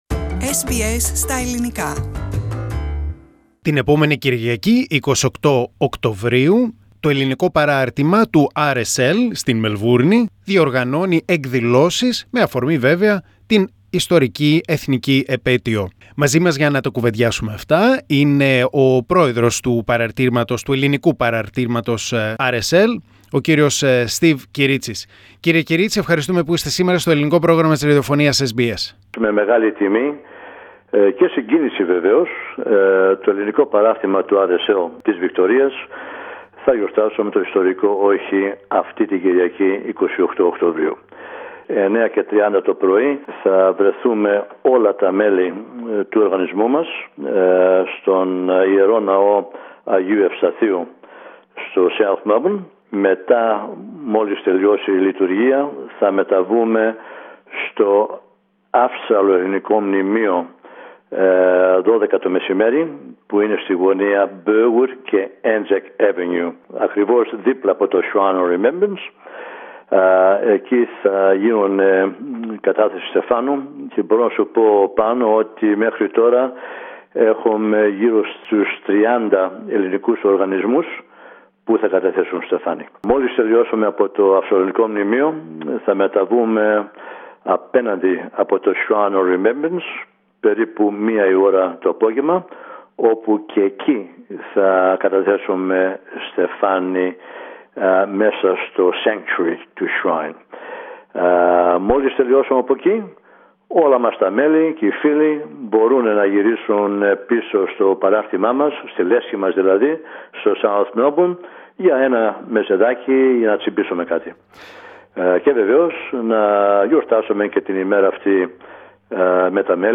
μίλησε στο Ελληνικό Πρόγραμμα για τις εκδηλώσεις της 28ης Οκτωβρίου